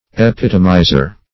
Epitomizer \E*pit"o*mi`zer\, n.
epitomizer.mp3